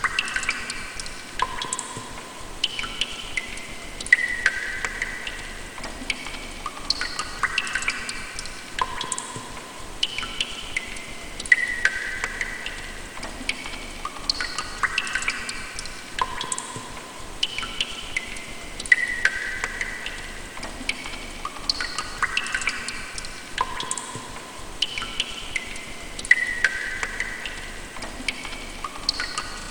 water-drips-echo-1.ogg